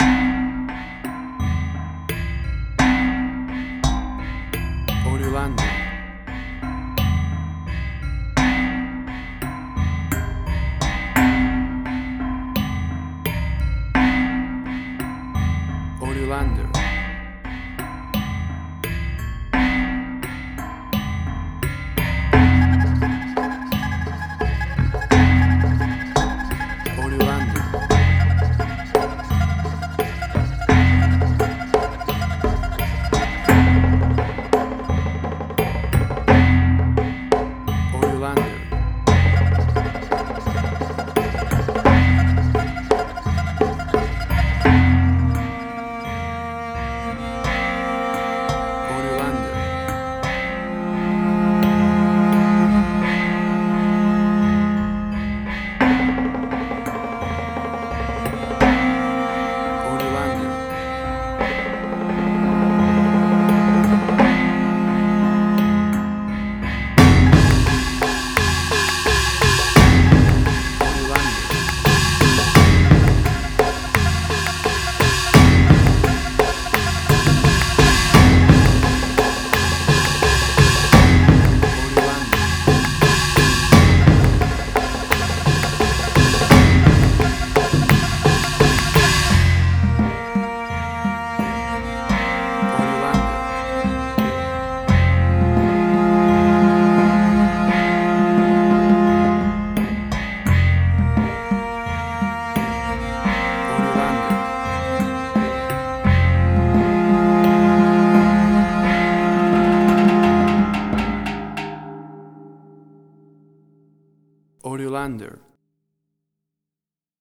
Chinese Action.
Tempo (BPM): 85